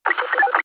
radio_on_1
radio-on-1.mp3